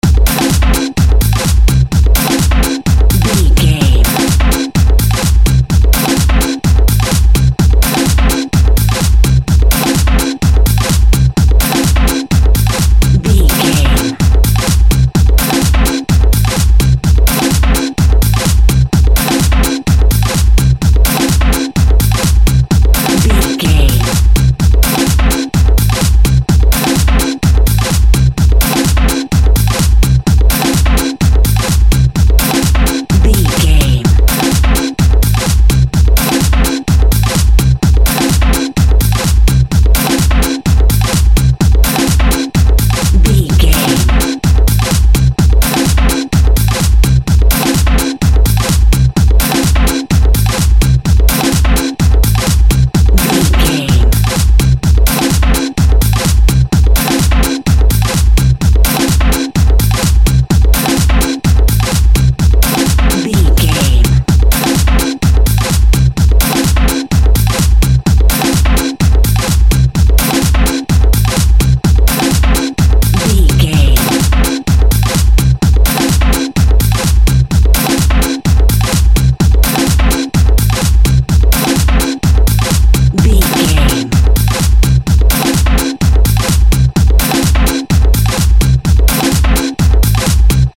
Fast paced
Aeolian/Minor
hard
intense
energetic
driving
repetitive
dark
synthesiser
drum machine
electro house
synth lead
synth bass